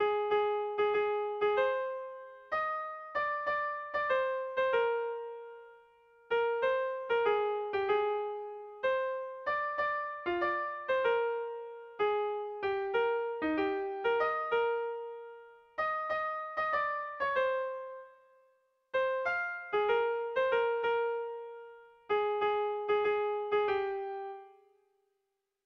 Euskal Herria zaigu - Bertso melodies - BDB.
Hiru abotsetara harmonizaturik dator..
Zortziko txikia (hg) / Lau puntuko txikia (ip)
ABDE